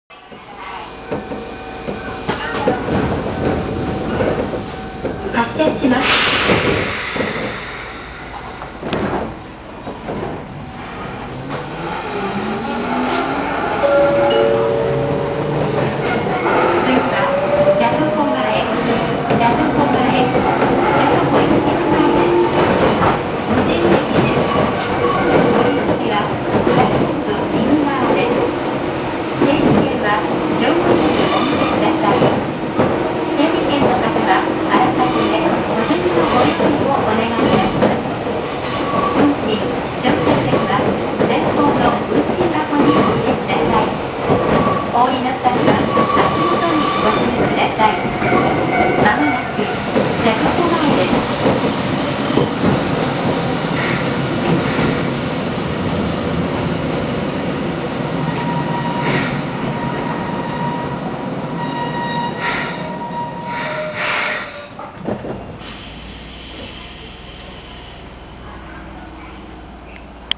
・8000系走行音
【岳南線】吉原本町→ジヤトコ前（1分10秒：383KB）
ごく普通の抵抗制御で、ワンマン運転故、自動放送もあります。速度を出す区間はそんなにありません。